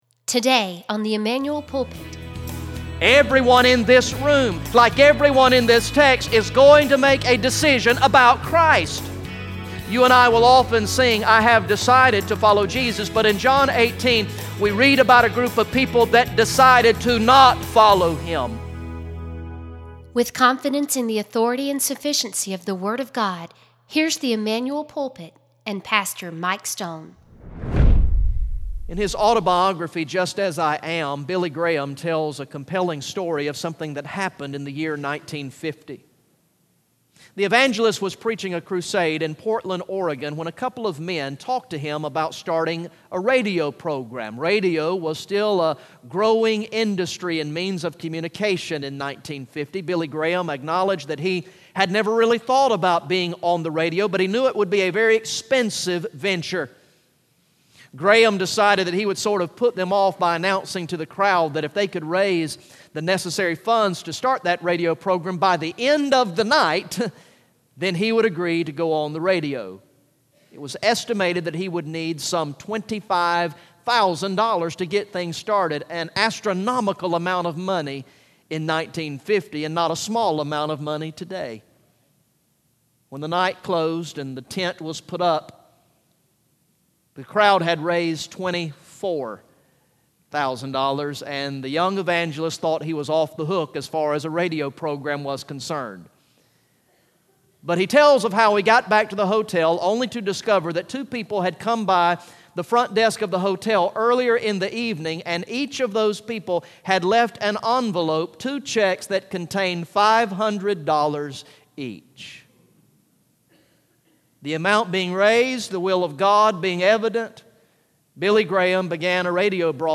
Message #69 from the sermon series through the gospel of John entitled "I Believe" Recorded in the morning worship service on Sunday, August 21, 2016